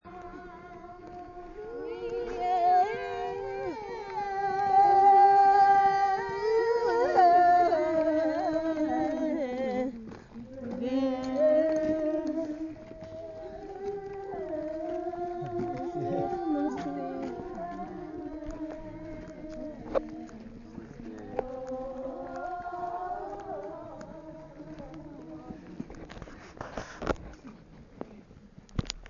women_walk_around_temple.mp3